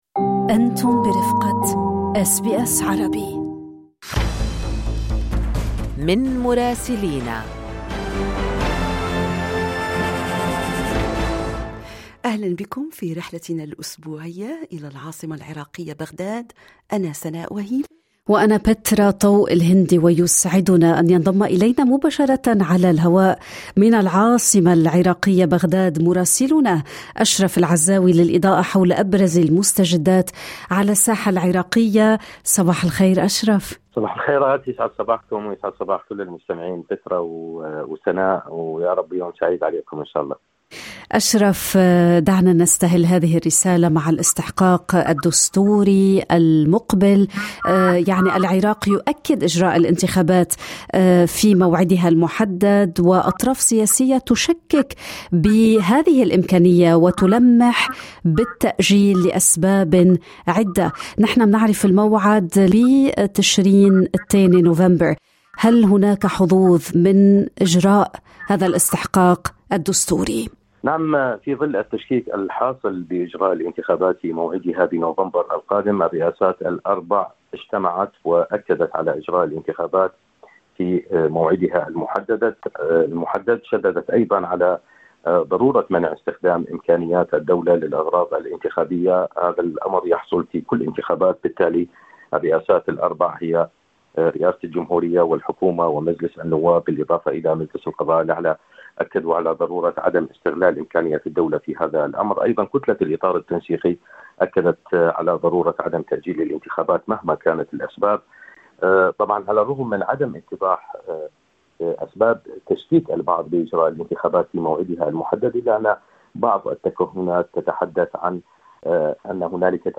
انتخابات متوترة وغموض في وفاة طبيبة علم النفس... التفاصيل في تقرير مراسلنا في بغداد